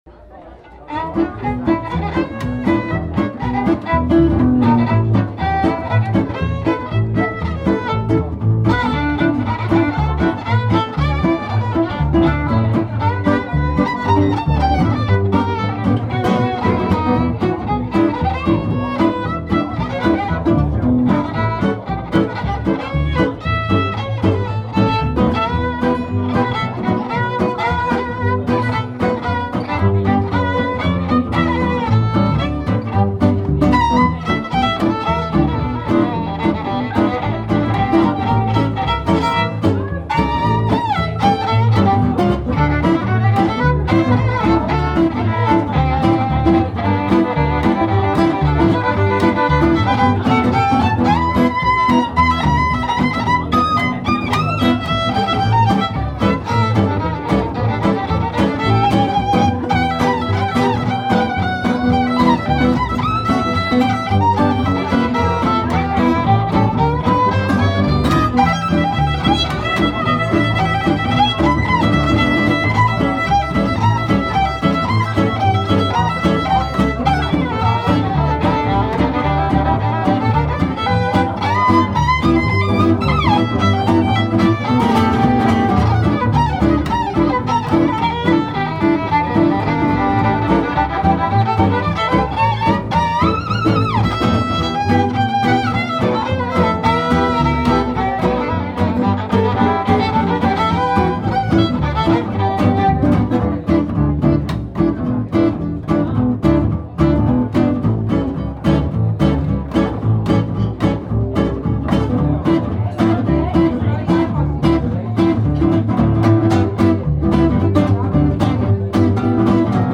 Live aus Laupen